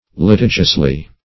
litigiously - definition of litigiously - synonyms, pronunciation, spelling from Free Dictionary Search Result for " litigiously" : The Collaborative International Dictionary of English v.0.48: Litigiously \Li*ti"gious*ly\, adv.
litigiously.mp3